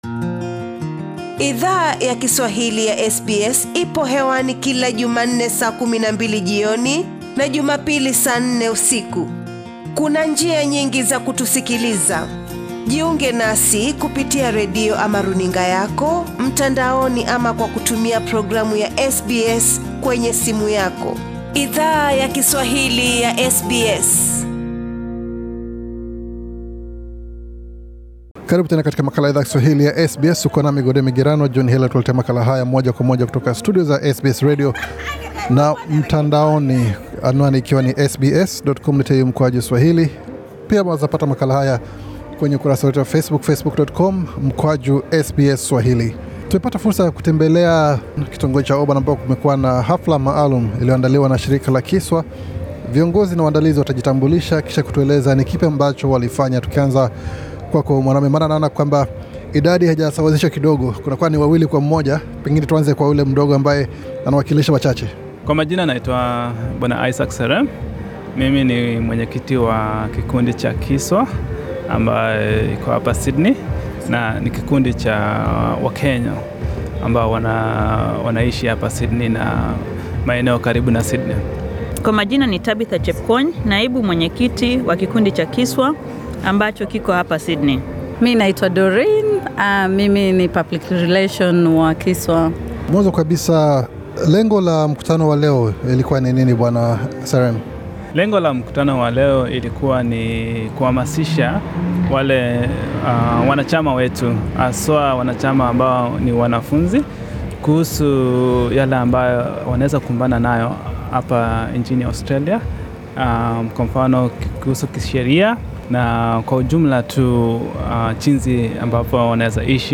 Idhaa ya Kiswahili ya SBS ilihudhuria mkutano maalum katika kitongoji cha Auburn, ambako shirika la KISWA lili alika wataalam tofauti, kuzungumza na wanachama wao kuhusu mada tofauti ambayo zinazo wahusu wanachama wao. Viongozi wa shirika hilo wali eleza Idhaa wa Kiswahili ya SBS kuhusu taarifa iliyo tolewa kwa wanachama.